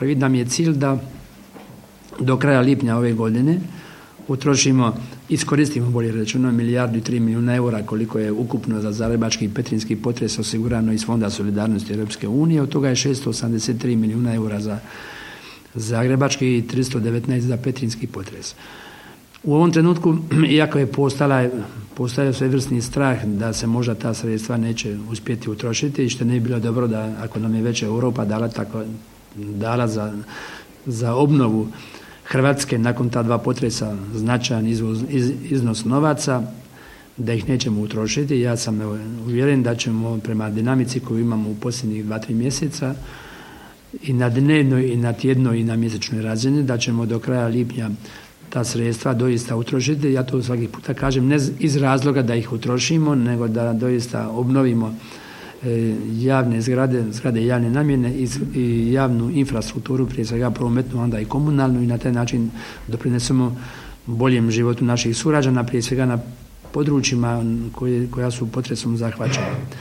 Napravljen je veliki iskorak nakon donošenja novog Zakona o obnovi na potresom pogođenim područjima, rekao je za svog posjeta Novskoj, potpredsjednik Vlade i ministar prostornog uređenja, graditeljstva i državne imovine Branko Bačić.